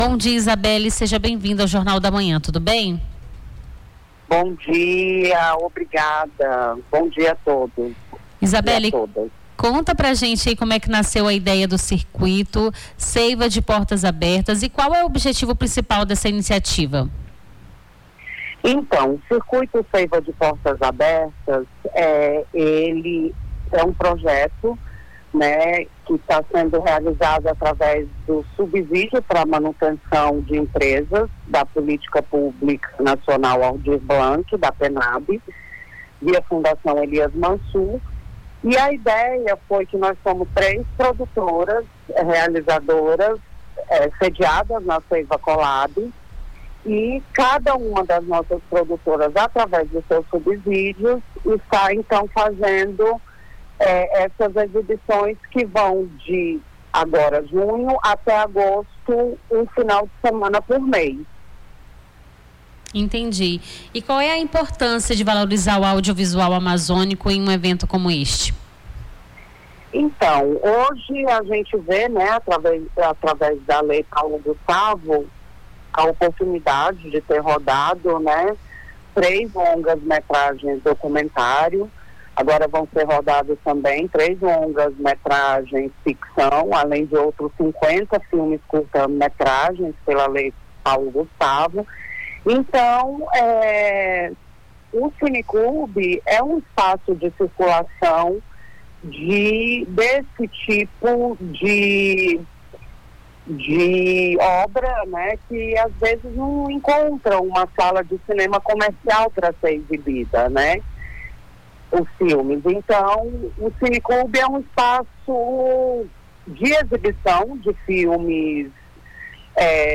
Nome do Artista - CENSURA - ENTREVISTA (SEIVA DE PORTAS ABERTAS) 27-06-25.mp3